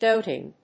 音節dót・ing 発音記号・読み方
/‐ṭɪŋ(米国英語), ˈdəʊtɪŋ(英国英語)/